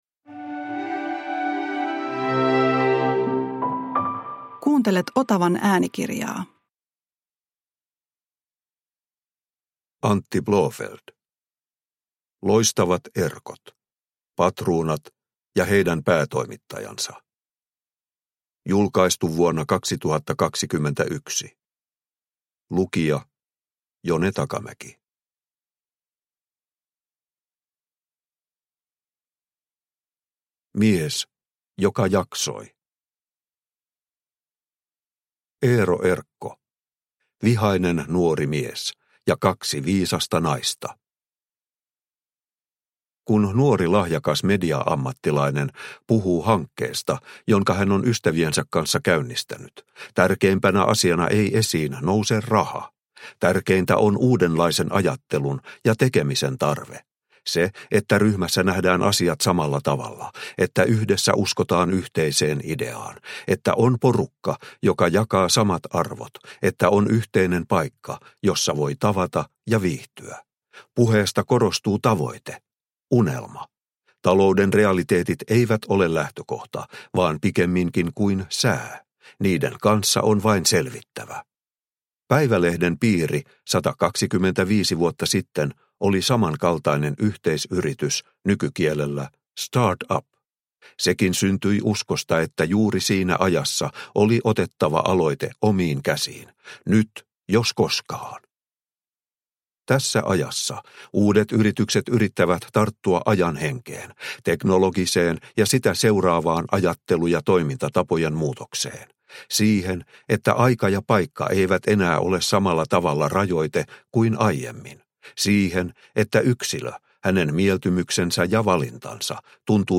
Loistavat Erkot – Ljudbok – Laddas ner